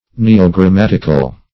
-- Ne`o*gram*mat"ic*al , a. [Webster 1913 Suppl.]